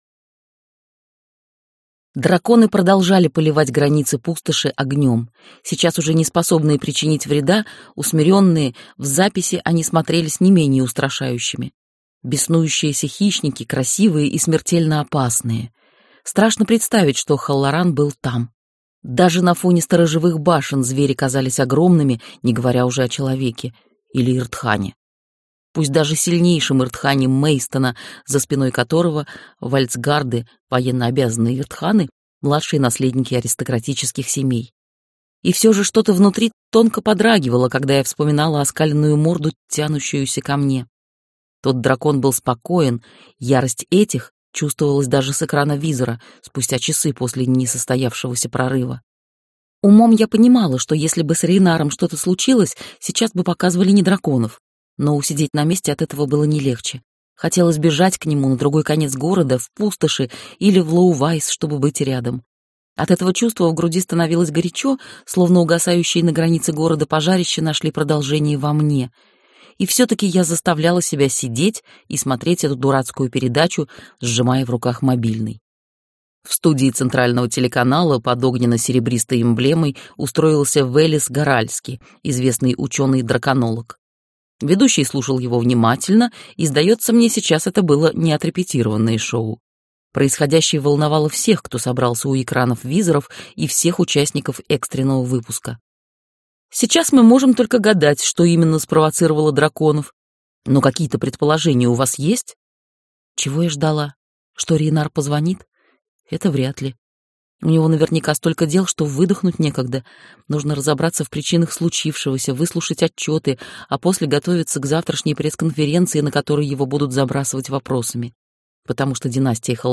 Аудиокнига Поющая для дракона. Пламя в твоих руках - купить, скачать и слушать онлайн | КнигоПоиск